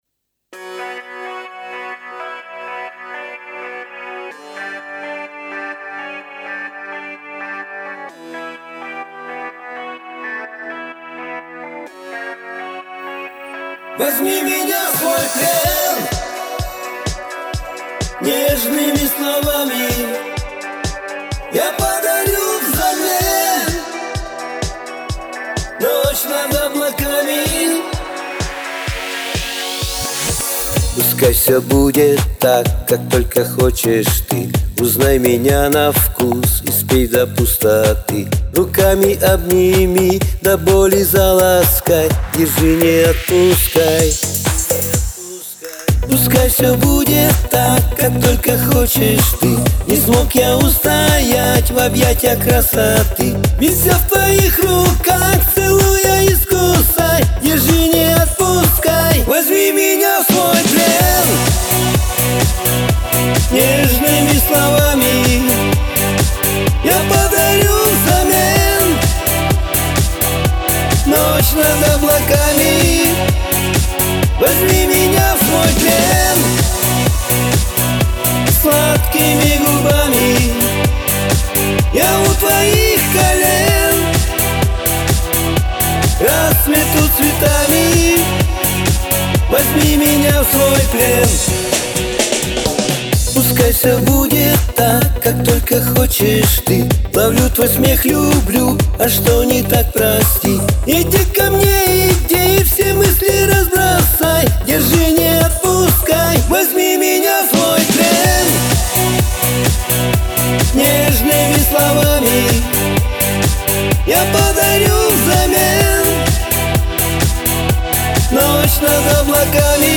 технично обработано, вот такое впечатление...v_naushnikah